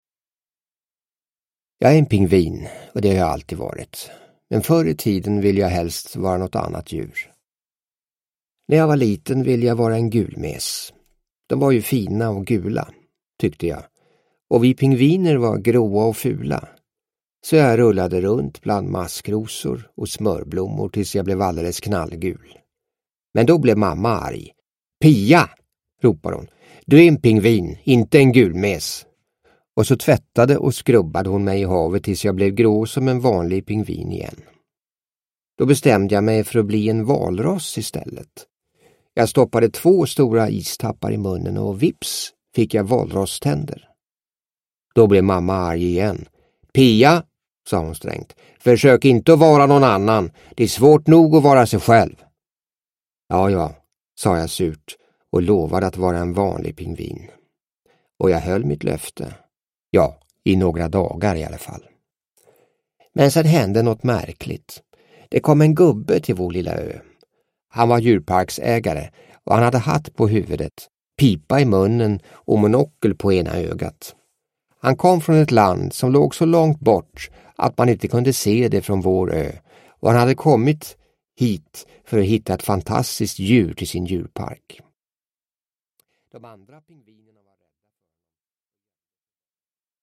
Pia Pingvin – Ljudbok – Laddas ner